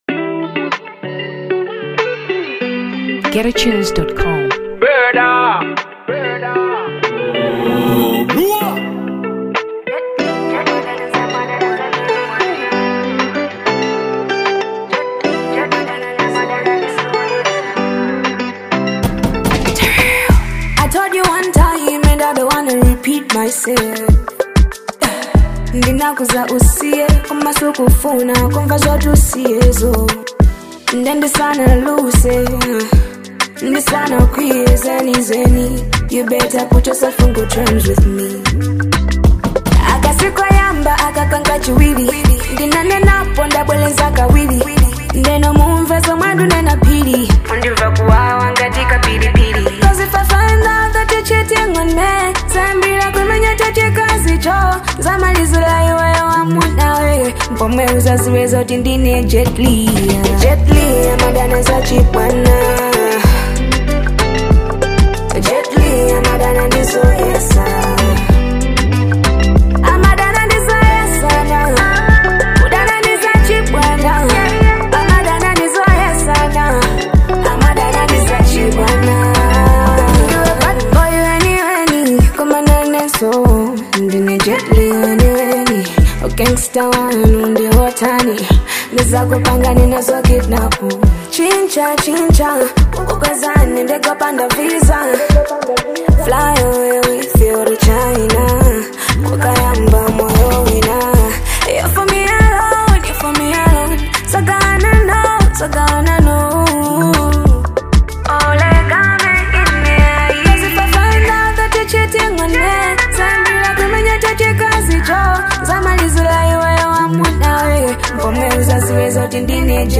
Afro Dancehall 2023 Malawi